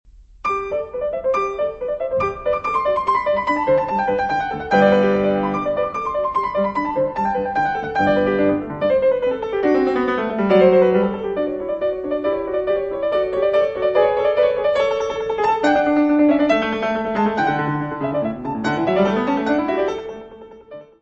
piano
Music Category/Genre:  Classical Music